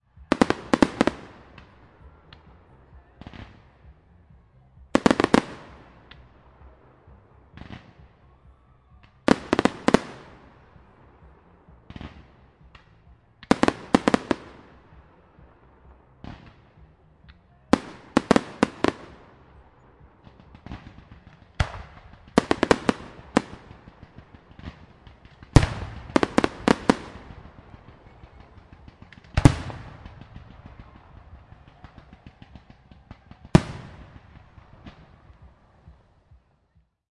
描述：烟花的未加工的音频显示在Godalming，英国。我用Zoom H1和Zoom H4n Pro同时录制了这个事件来比较质量。令人讨厌的是，组织者还在活动期间抨击了音乐，因此安静的时刻被遥远的，虽然模糊不清的音乐所污染。
声音是在2017年11月3日使用“H1 Zoom录音机”录制的。